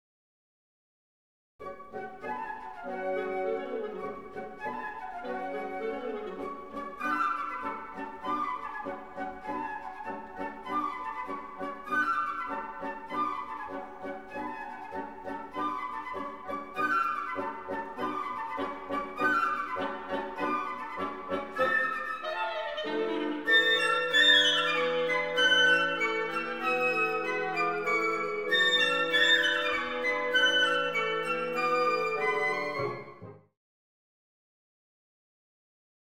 20-Symphony-No.-4-Third-Movement-Flute-Tchaikovsky.m4a